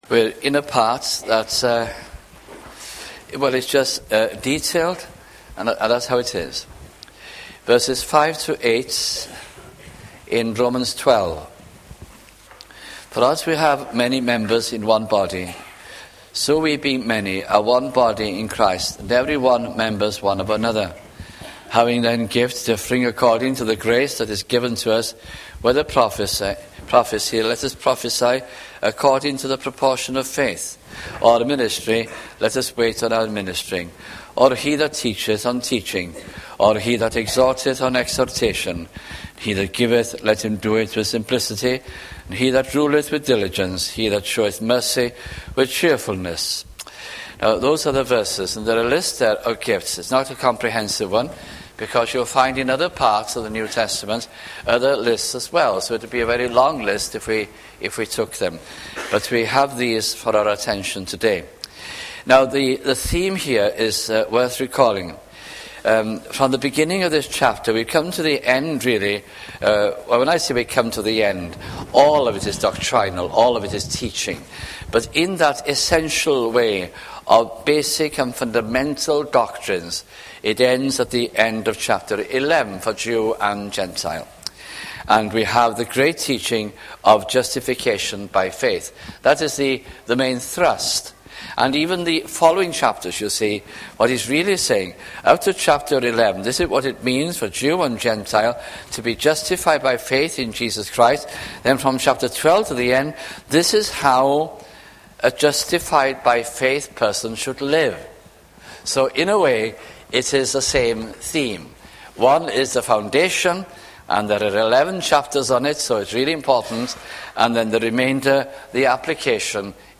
» Romans Series 1987 - 1988 » sunday morning messages